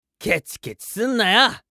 熱血系ボイス～戦闘ボイス～
【アイテム使用2】